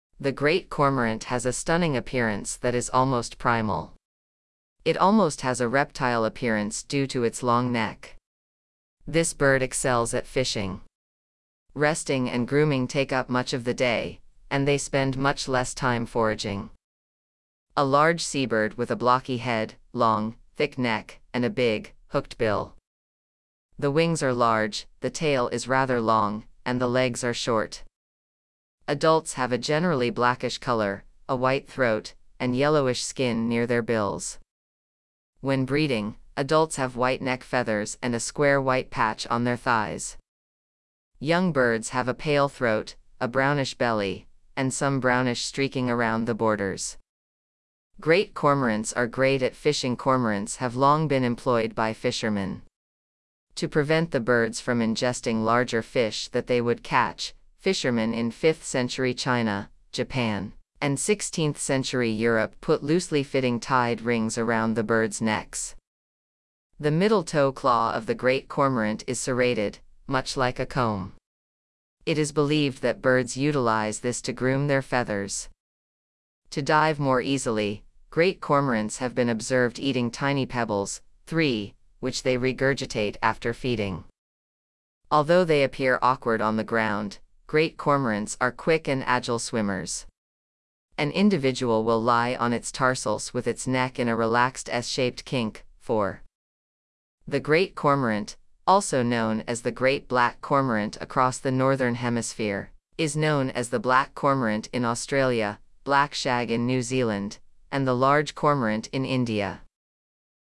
Great Cormorant
Great-Cormorant.mp3